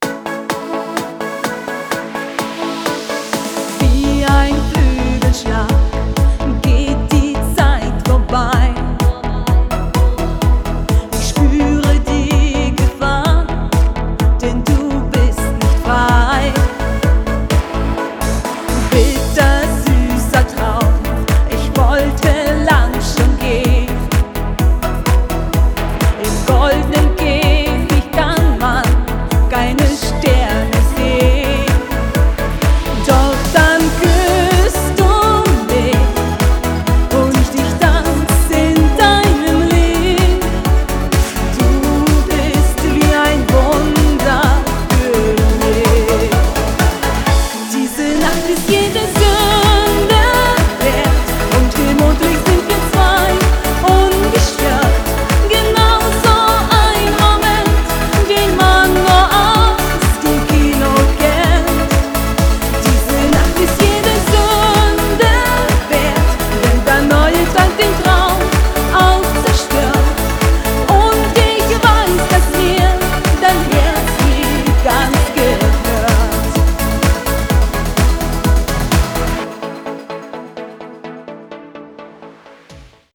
Hochzeitssängerin
Berührender Live-Gesang